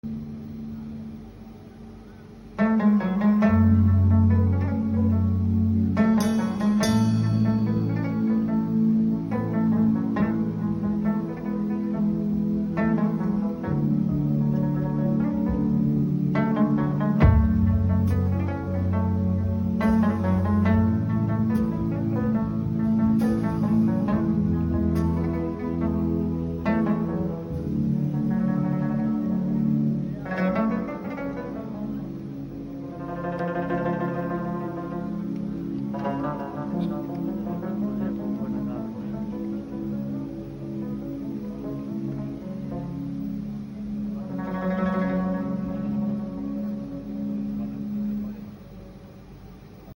Rabab Cover Song